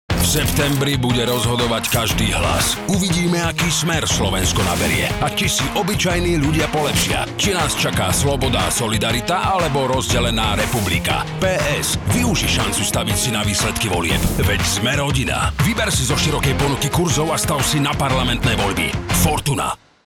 Cieľom bolo odpromovať nový produkt v ponuke stávkovej kancelárie: možnosť staviť si na výsledky Parlamentných volieb 2023. Našou úlohou bolo vytvoriť kreatívny návrh na kampaň, ktorej súčasťou má byť aj 20“ rádiospot.
fortuan_volby_2023_radio.mp3